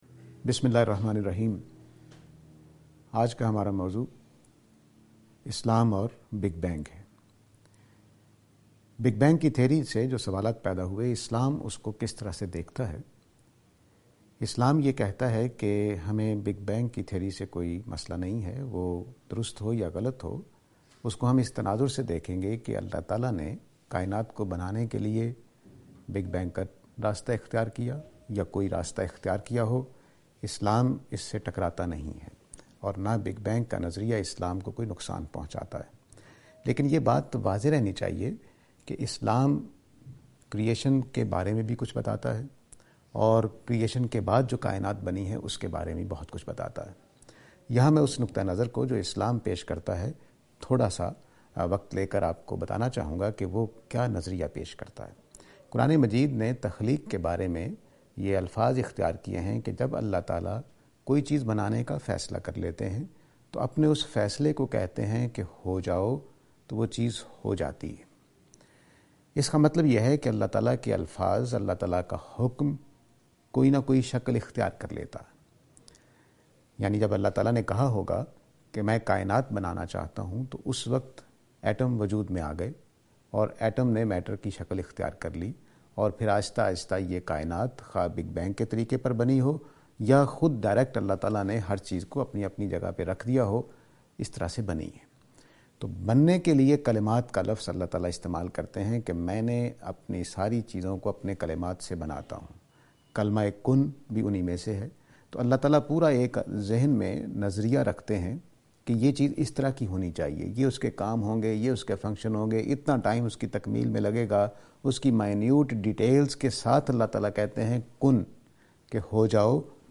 This lecture is and attempt to answer the question "Creation of Universe: Quranic perspective".